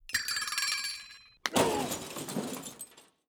cartoon
Human Burping 3